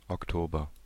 Ääntäminen
Synonyymit Gilbhart Ääntäminen : IPA: /ɔk.ˈtoː.bɐ/ Haettu sana löytyi näillä lähdekielillä: saksa Käännös Ääninäyte Substantiivit 1. oktober {en} Artikkeli: der .